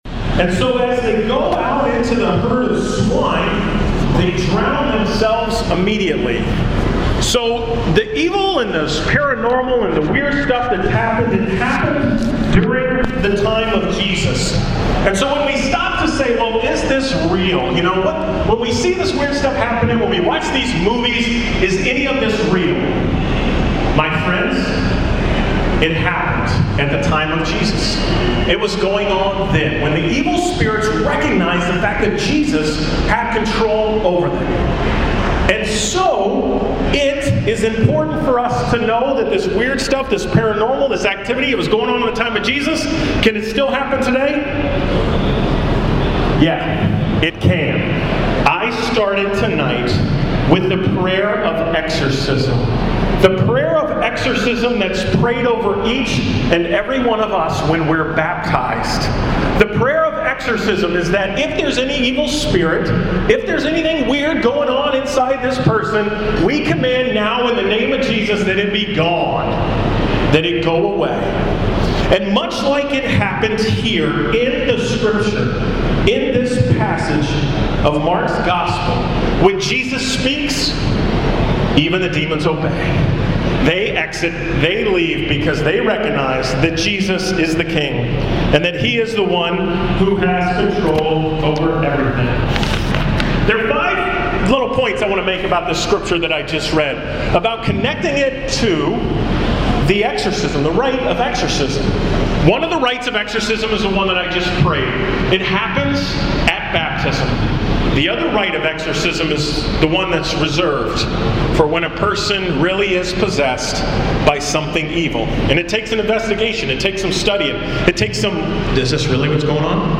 From the Theology on Tap about demons, evil spirits and the paranormal on Thursday, September 13th (about a 30 minute talk for young adults)
*I did not record the entire opening prayer, which was Mark 5:1-15.*